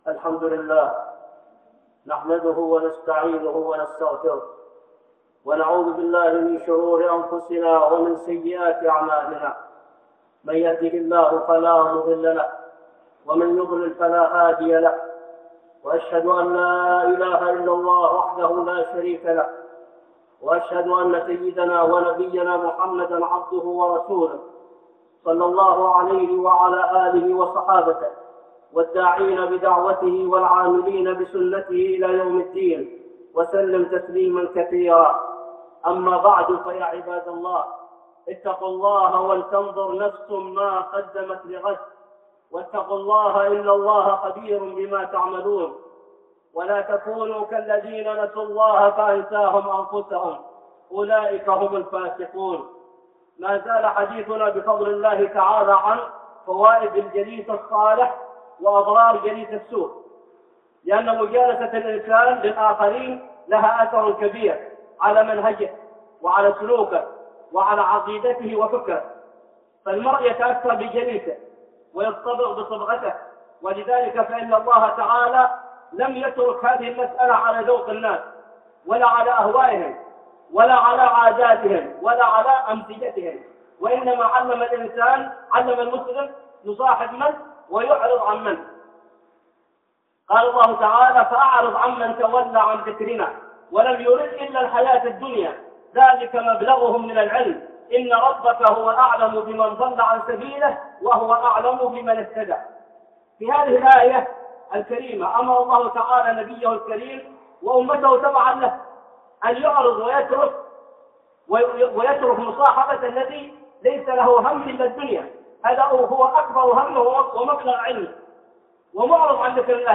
(خطبة جمعة) حامل المسك ونافخ الكير 2